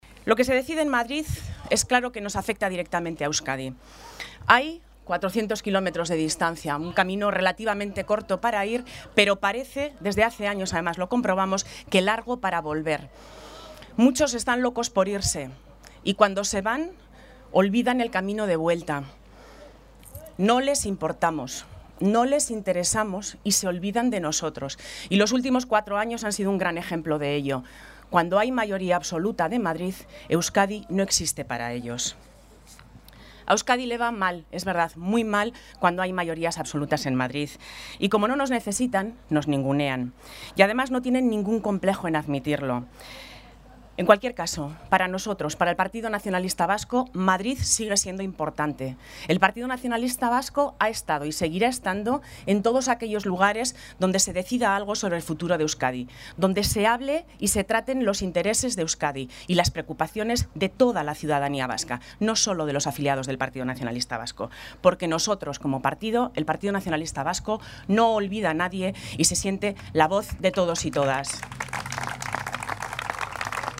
Aurkezpena gaur goizean egin da Bilbon eta Bizkaiko exekutibako kideak, herri kargudunak eta abenduko hauteskundeetarako hautagaiak elkartu dira bertan.